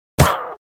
Dźwięk obrażeń wiedźmy nr.2
Witchhurt2.wav